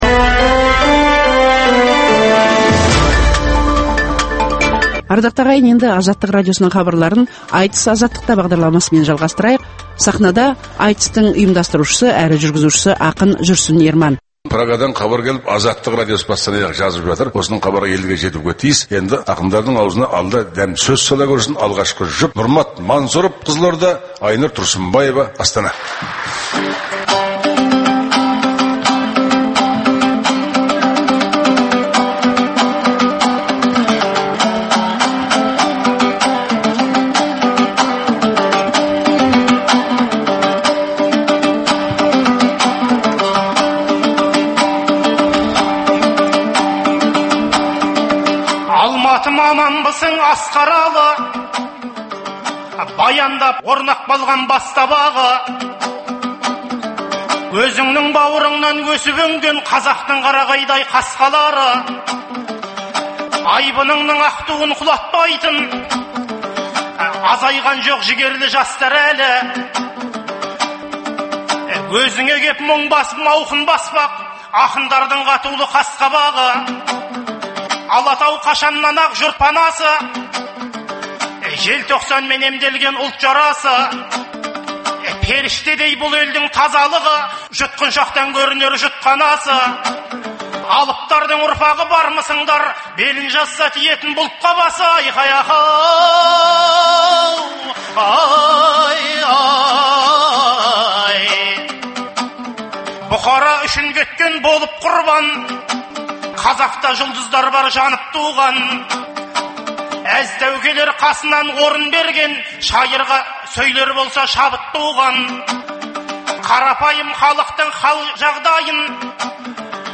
Айтыс - Азаттықта
Өткен жылы Наурыз мерекесі күндері Алматыдағы студенттер сарайында
ақындар айтысындағы